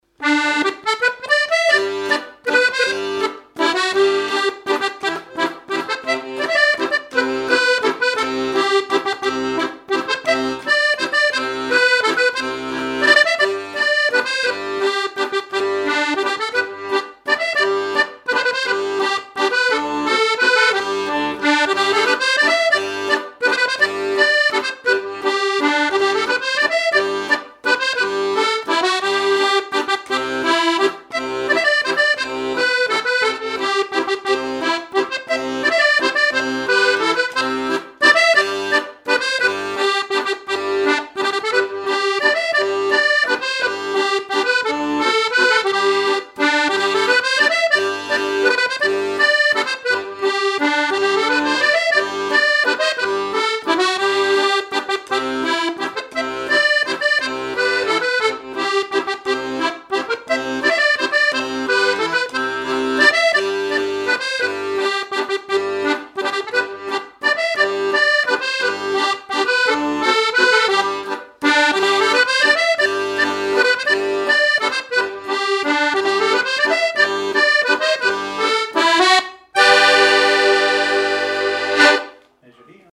Mémoires et Patrimoines vivants - RaddO est une base de données d'archives iconographiques et sonores.
danse : mazurka
Pièce musicale inédite